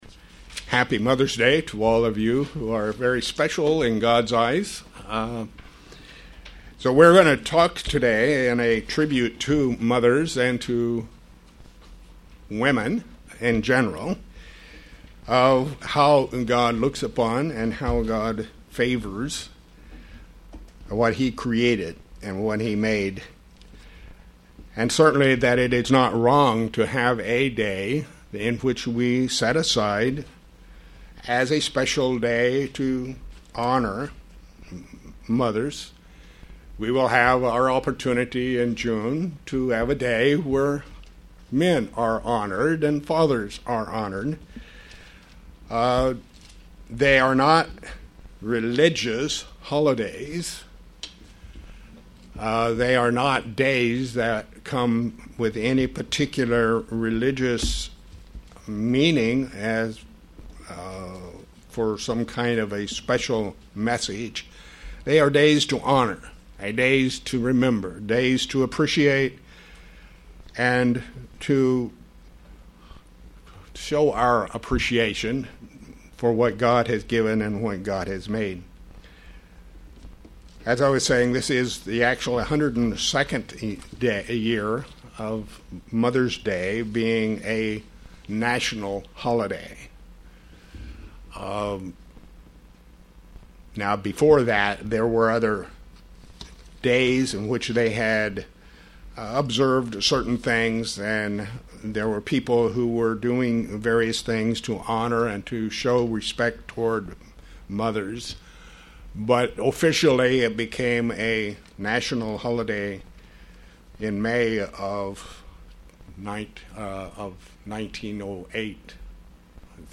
Given in York, PA